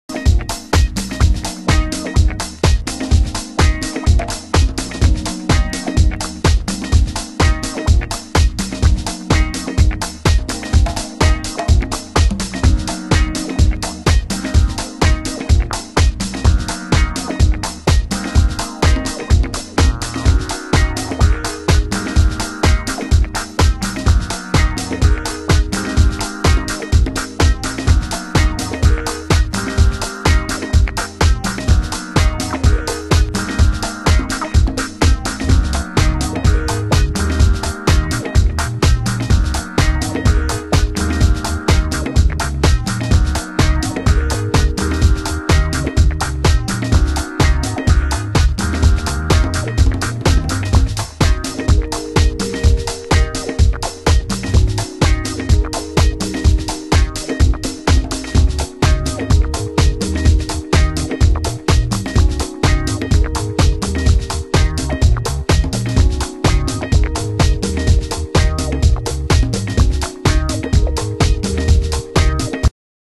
Acapella
Dubb